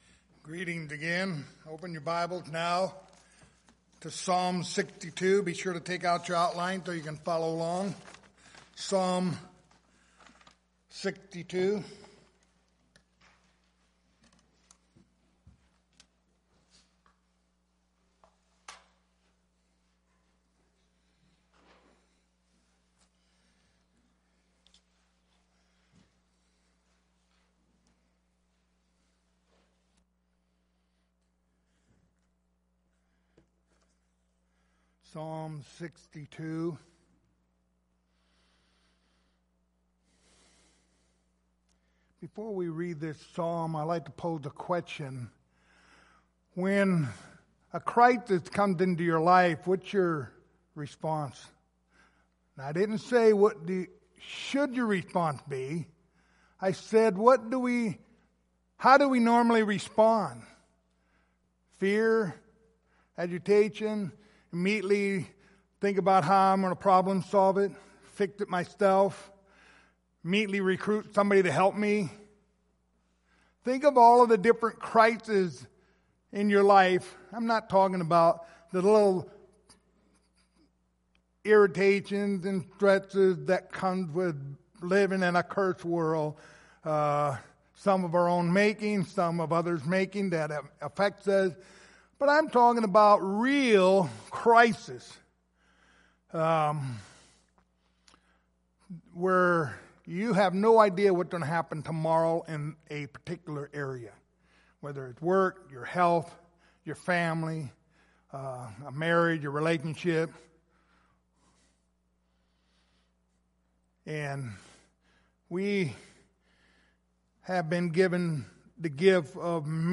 The book of Psalms Passage: Psalm 62:1-12 Service Type: Sunday Morning Topics